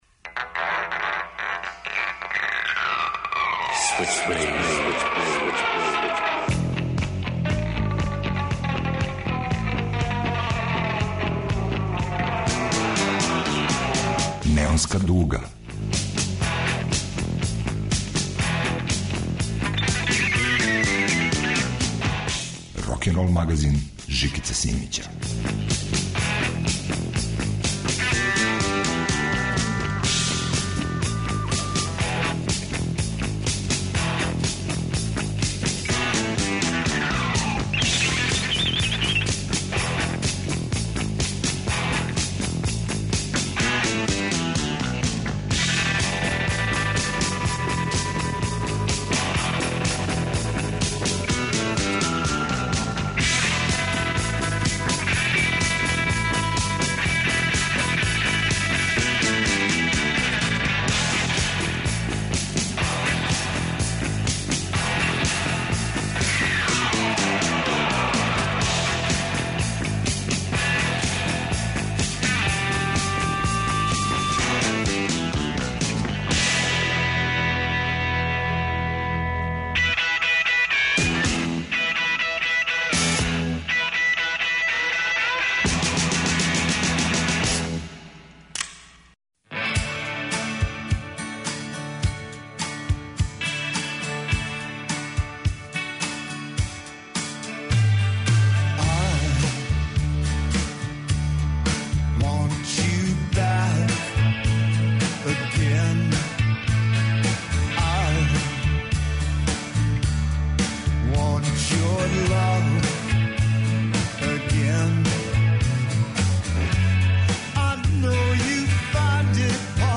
Неонска дуга представља омиљене обраде у 2016. години.
Рокенрол као музички скор за живот на дивљој страни. Вратоломни сурф кроз време и жанрове.